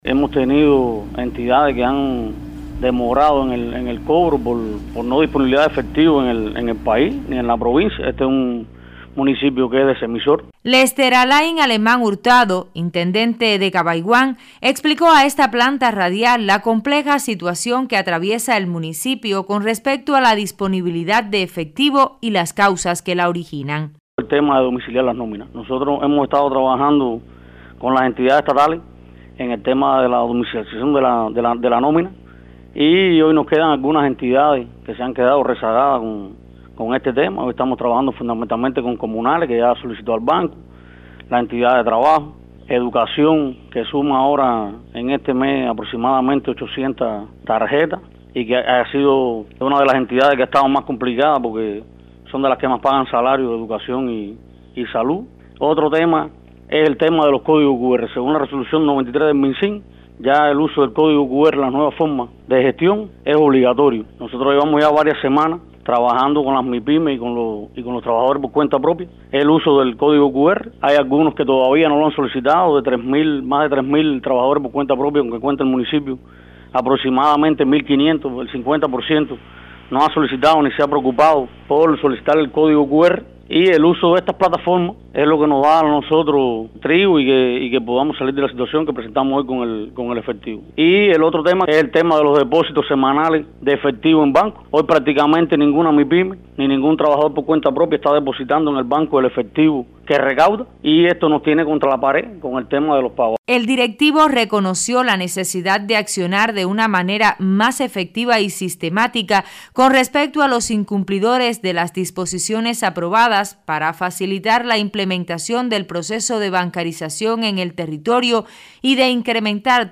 Léster Alain Alemán Hurtado, Intendente de Cabaiguán, explicó a esta página web la compleja situación que atraviesa el municipio con respecto a la disponibilidad de efectivo y las causas que la originan